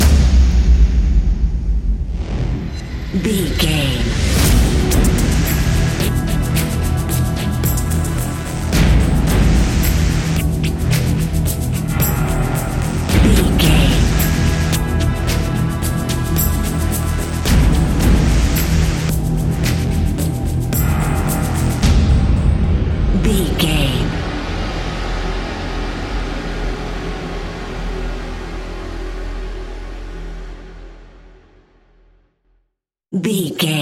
Ionian/Major
E♭
industrial
dark ambient
drone
synths